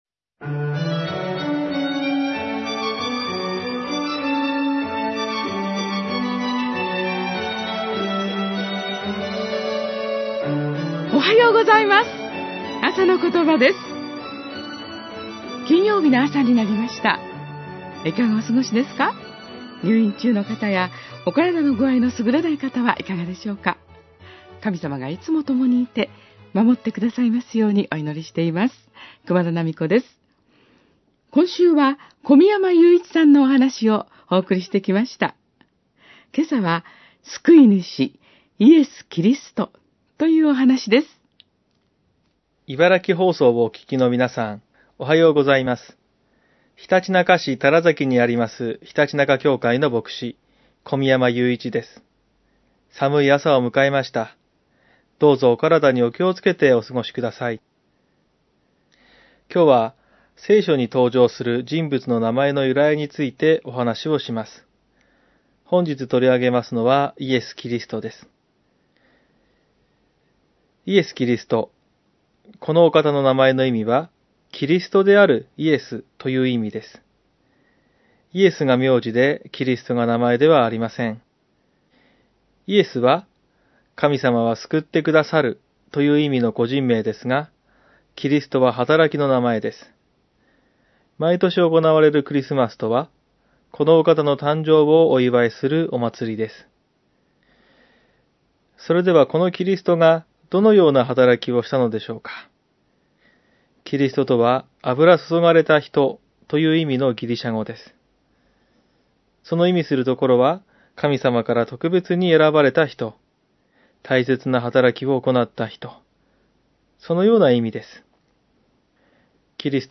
メッセージ： 　救い主　ーイエス・キリスト